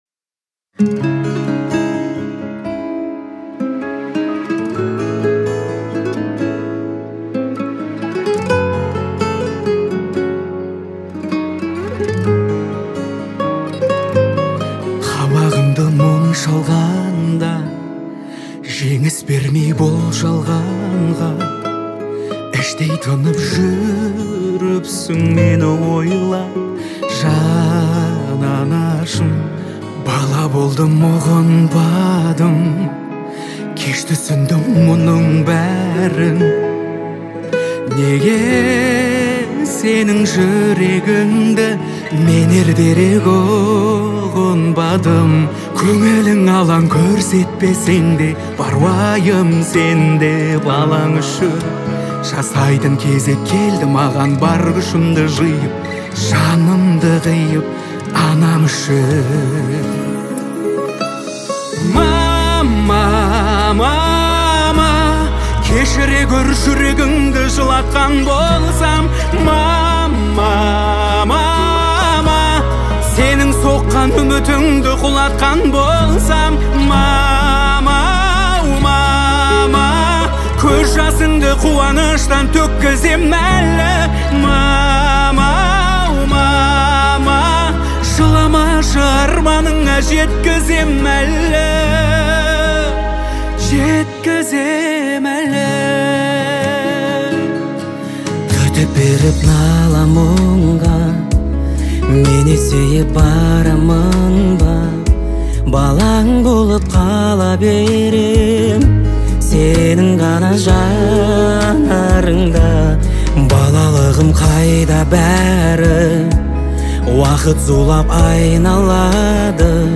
Категория: Казахские,